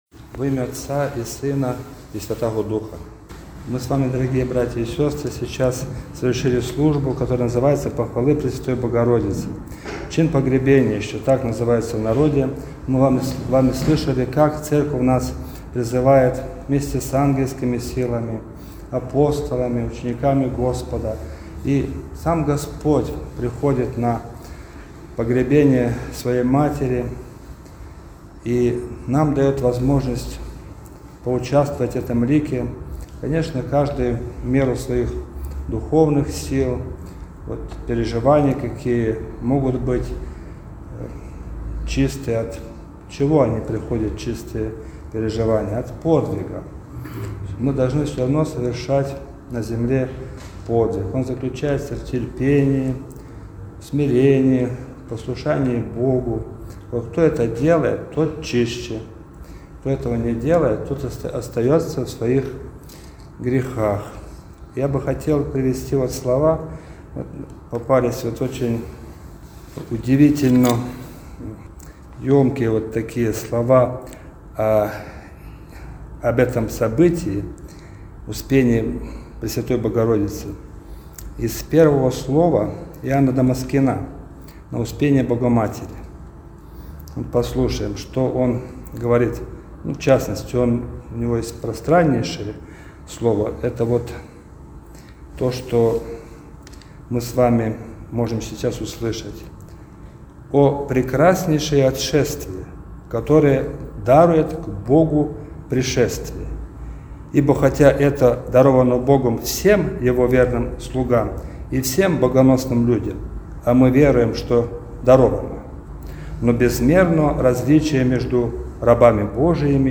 Чин-погребения.mp3